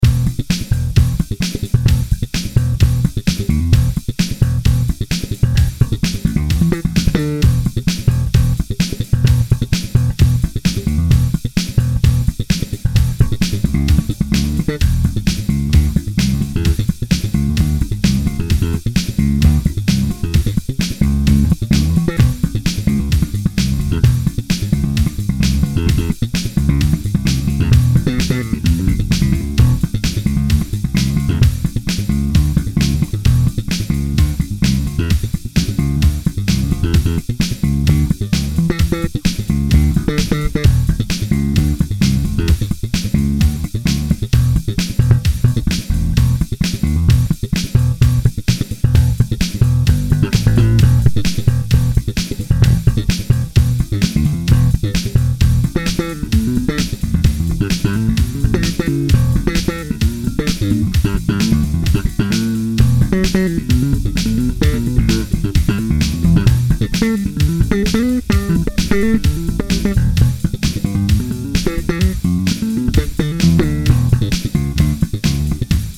Style FUSION